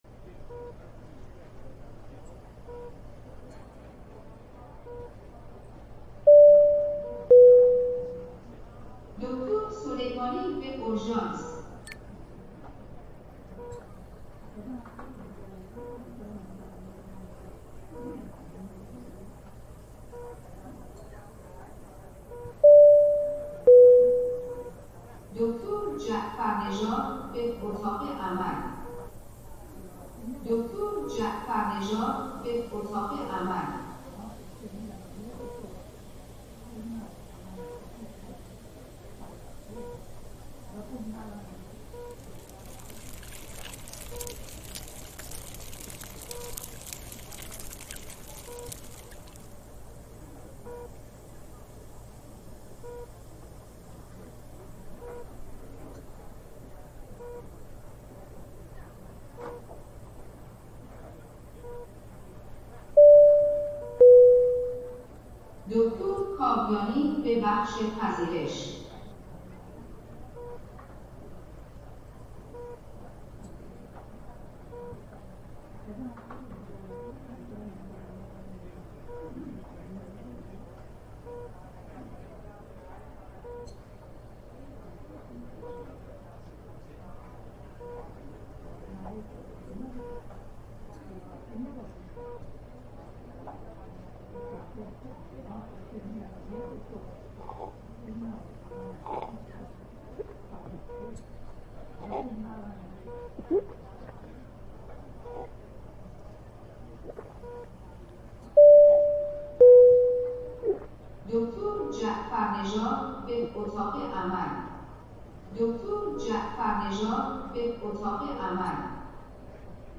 دانلود آهنگ بیمارستان ایرانی از افکت صوتی طبیعت و محیط
دانلود صدای بیمارستان ایرانی از ساعد نیوز با لینک مستقیم و کیفیت بالا
جلوه های صوتی